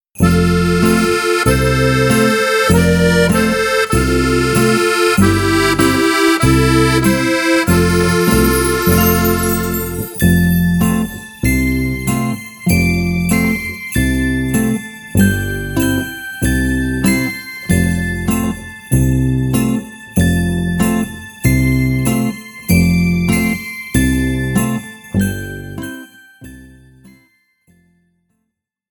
Besetzung: 1-2 Sopranblockflöten